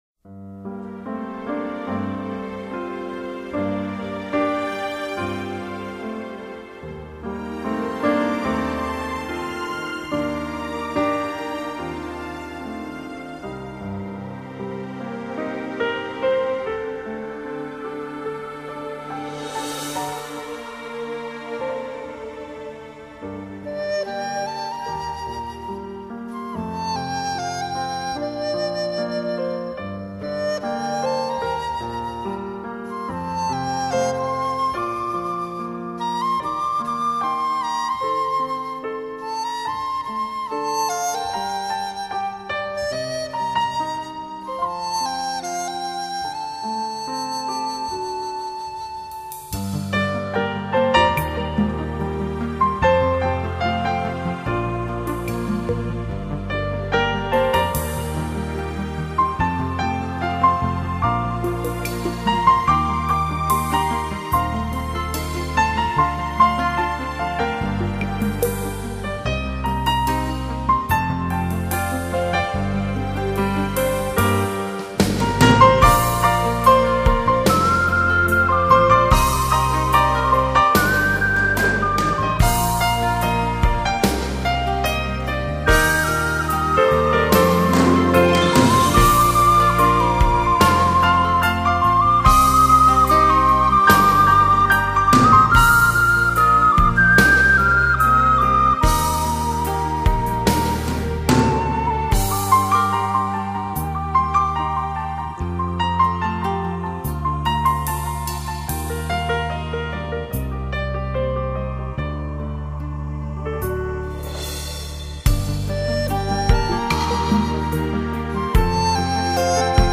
熟悉的流行旋律，听着悠扬的笛子，轻轻的弹奏，很舒畅啊。
这音乐让人感觉听温馨的。
悠扬的笛子声音，谢谢啦。